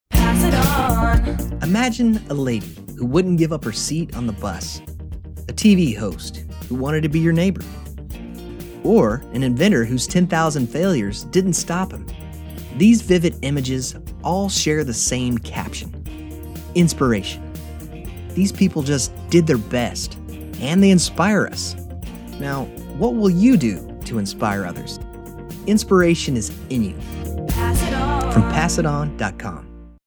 We’re excited to share our new PSA Radio spots that will inspire and motivate.